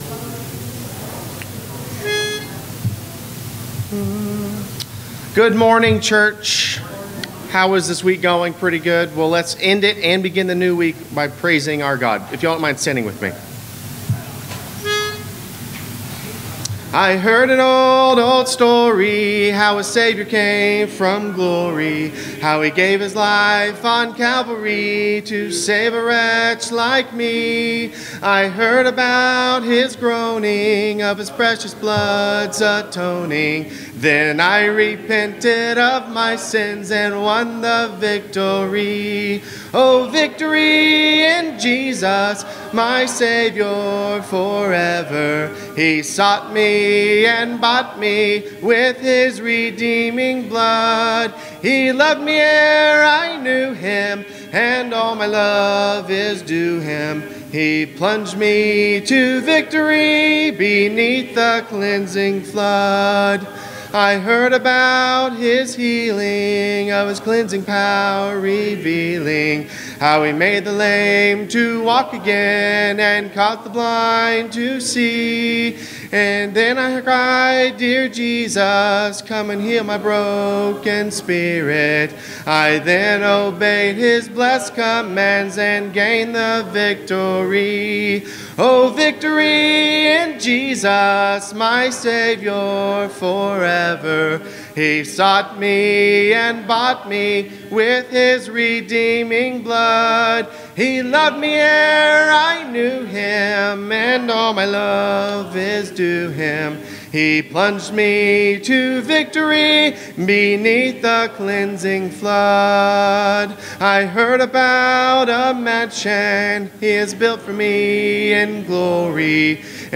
Recorded at live service on July 18, 2021.